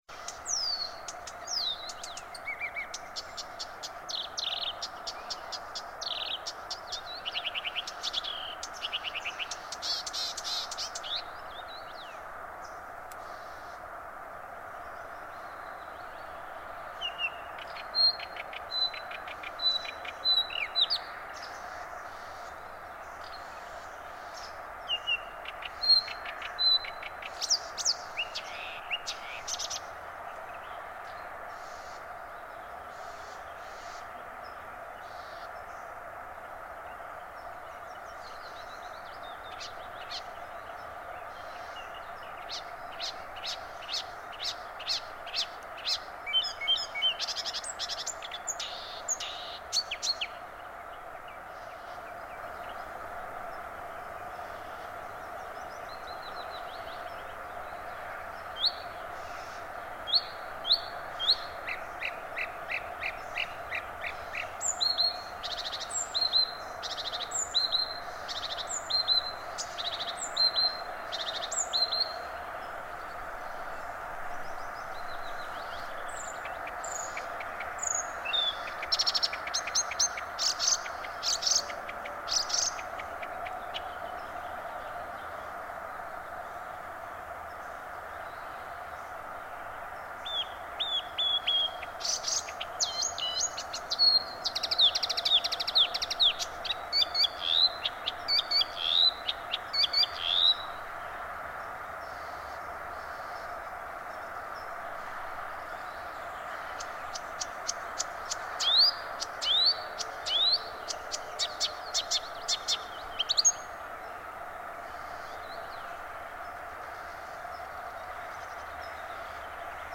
Imitations in recorded song of hybrid Marsh x Blyth's Reed Warbler Acrocephalus palustris x dumetorum, Espoo, Finland, 19 June 2003
0:07 one very clear imitation of a full song of Rattling Cisticola and 'tchep-tchep' calls of Scarlet-chested Sunbird
0.47 nice fluty three-note song (Blyth's Reed Warbler style) and other imitation of a possible turdid
2:50 Eurasian Magpie Pica pica call alternating with ascending whistle of Red-pate Cisticola. This is a very typical construction for a Marsh Warbler Acrocephalus palustris. They like to alternate very contrasting notes taken from different species.
3:04 'tju-tju' series (turdid?), Nightingale type calls, one 'rue' call of Blue-cheeked Bee-eater Merops persicus. This section is a good example of the Espoo bird sounding like Blyth's Reed Warbler (only slightly faster).